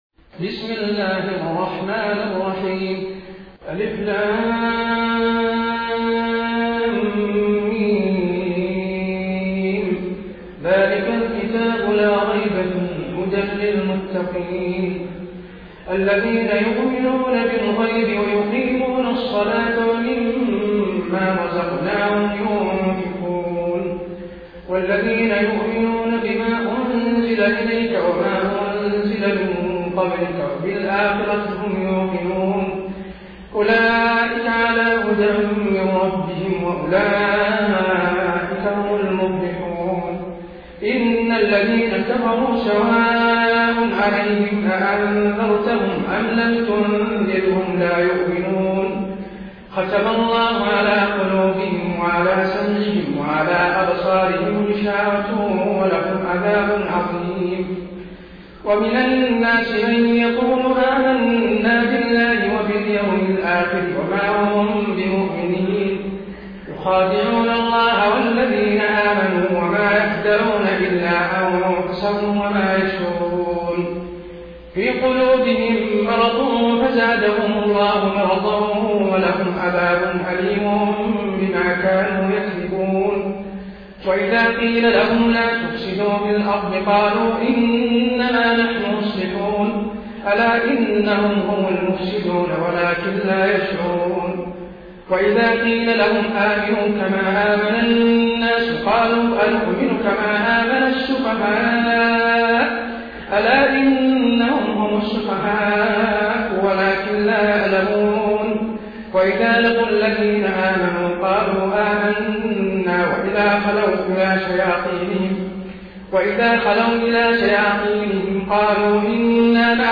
Quran recitations
taraweeh-1433-madina